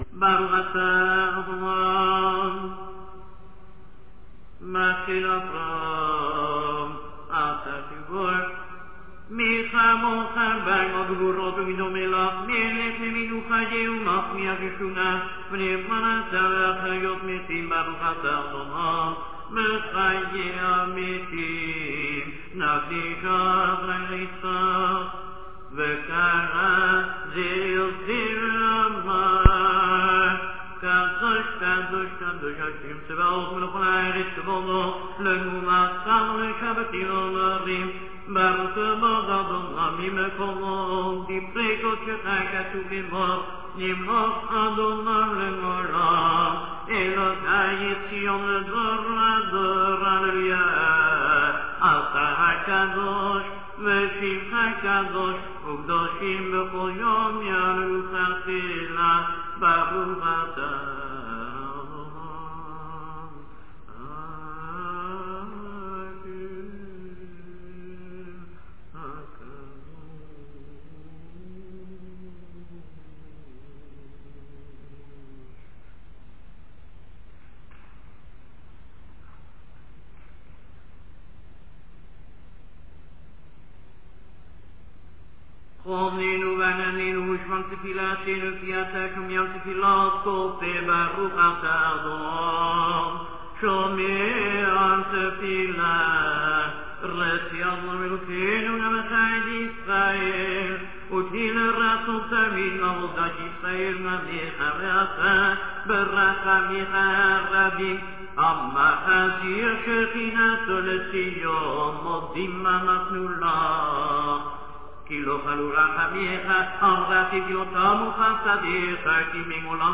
chazzan starts aloud till מחיה המתים, then קדושה.
From there aloud till the end.